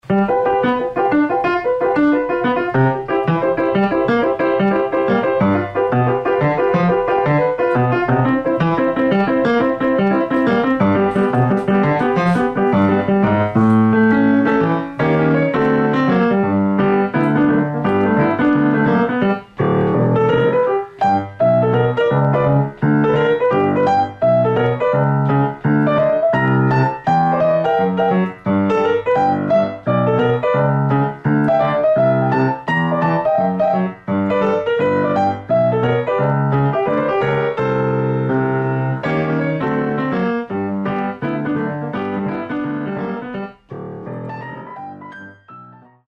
Style: Barrelhouse Piano